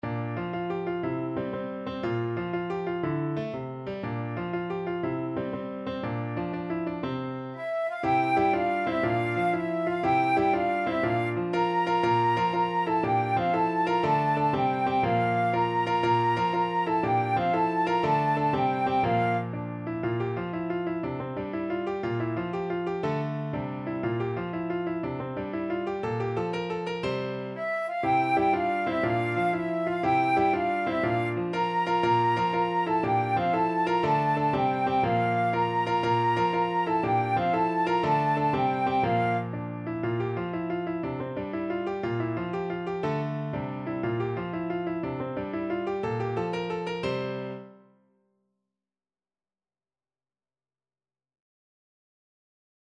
Classical Halle, Adam de la J'ai encore une tel paste from Le jeu de Robin et Marion Flute version
Flute
6/8 (View more 6/8 Music)
F major (Sounding Pitch) (View more F major Music for Flute )
With energy .=c.120
Classical (View more Classical Flute Music)